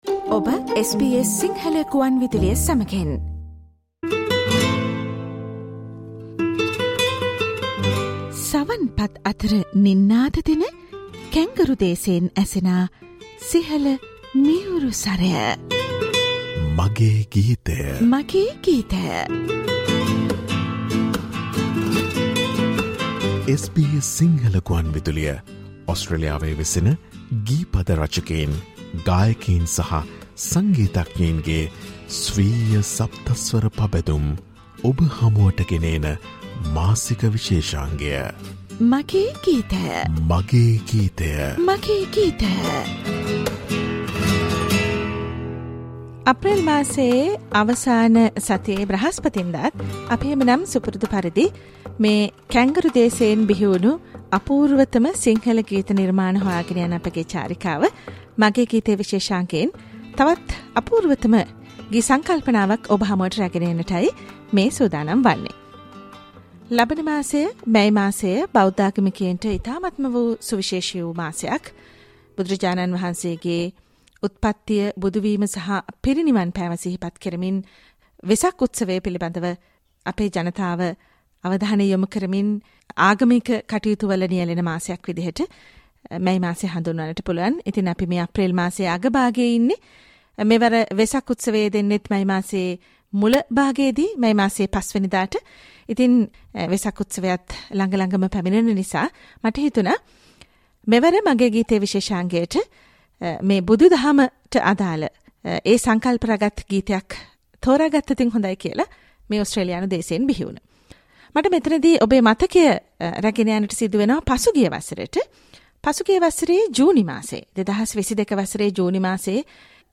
SBS Sinhala ‘My song’ monthly musical program